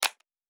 pgs/Assets/Audio/Sci-Fi Sounds/Mechanical/Device Toggle 08.wav at 7452e70b8c5ad2f7daae623e1a952eb18c9caab4
Device Toggle 08.wav